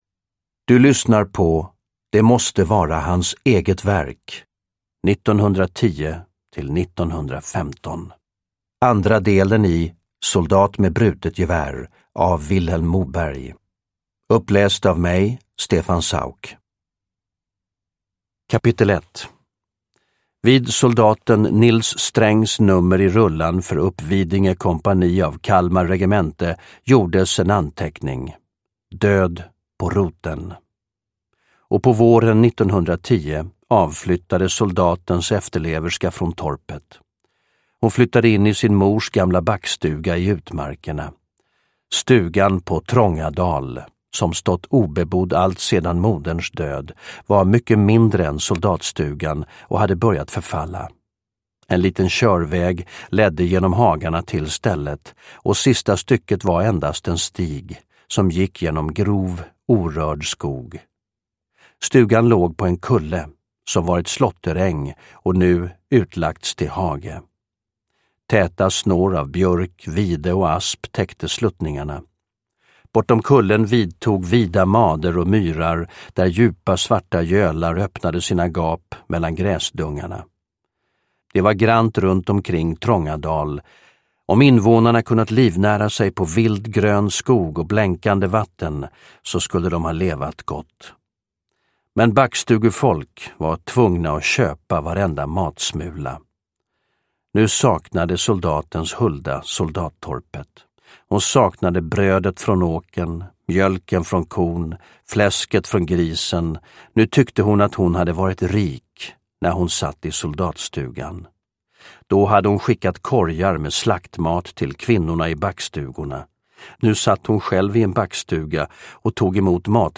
Soldat med brutet gevär 2 : Det måste vara hans eget verk: 1910-1915 – Ljudbok – Laddas ner
Uppläsare: Stefan Sauk